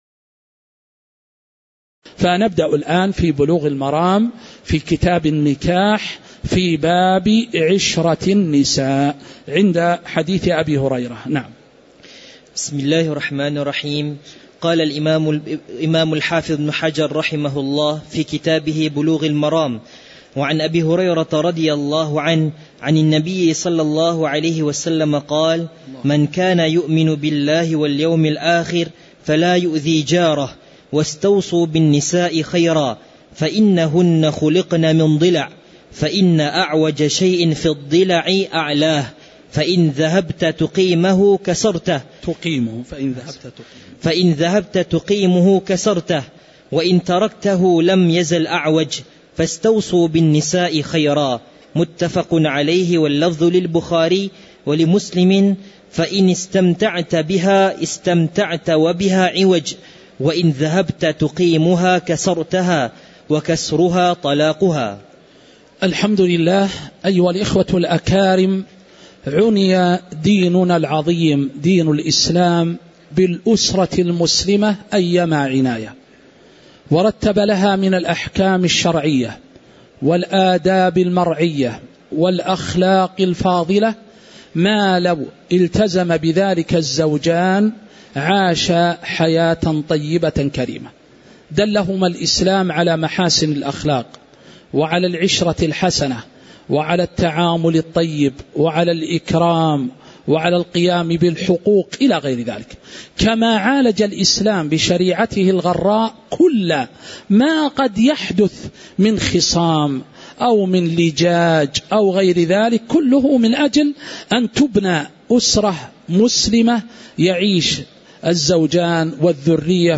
تاريخ النشر ٤ شوال ١٤٤٦ هـ المكان: المسجد النبوي الشيخ